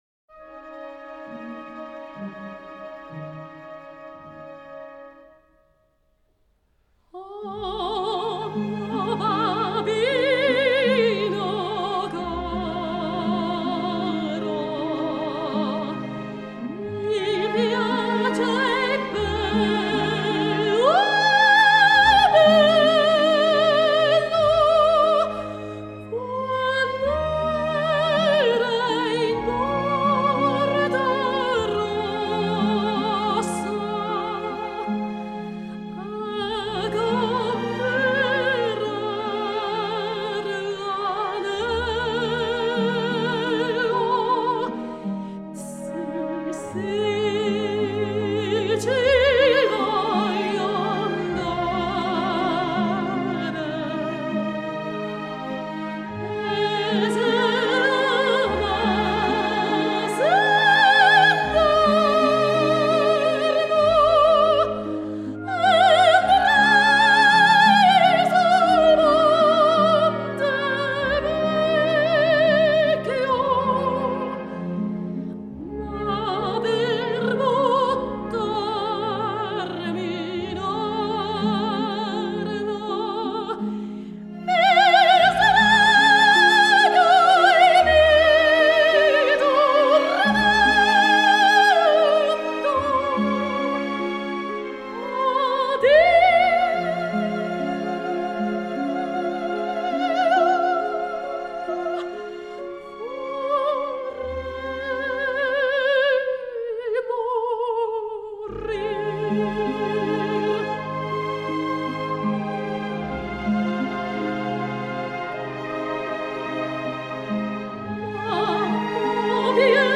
by Arie der Lauretta aus Gianni Schicchi
02-O-mio-babbino-caro-Arie-der-Laureatta-Puccini.mp3